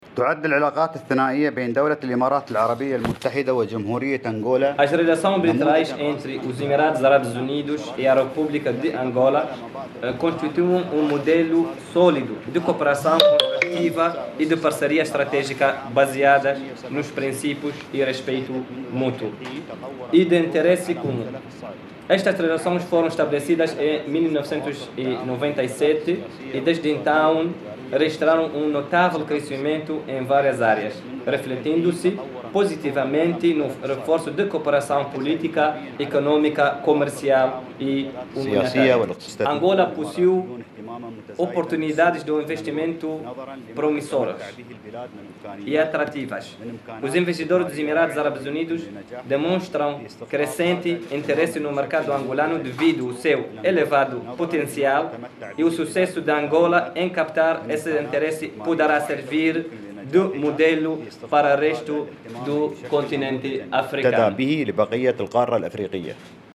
E o Embaixador dos Emirados Árabes Unidos, em Angola, Salam Ali Shami, mostra o interesse do seu país em continuar a investir no mercado Angolano, dadas as potencialidades e as oportunidades que o nosso país oferece.
SALAM-ALI-SHAMI-06-HRS.mp3